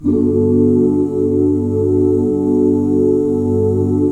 GMAJ7 OOO.wav